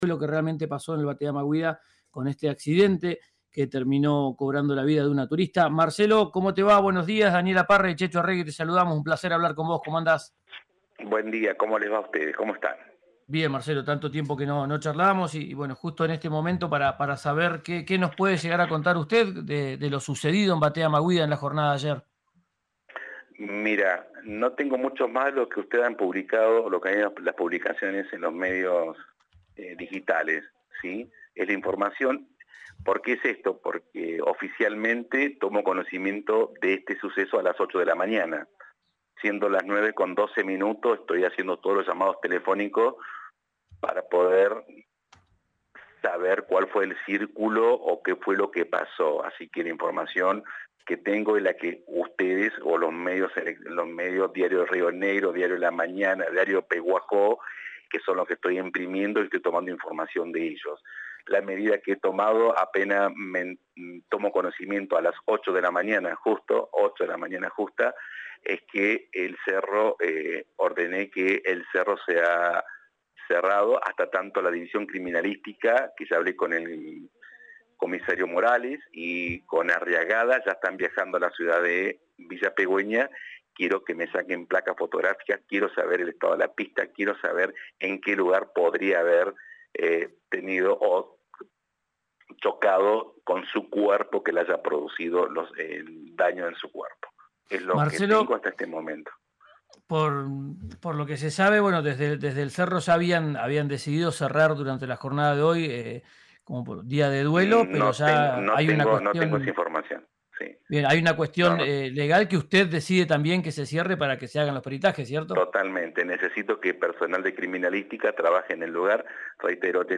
Escuchá a Marcelo Jofré, fiscal a cargo del caso, en RÍO NEGRO RADIO: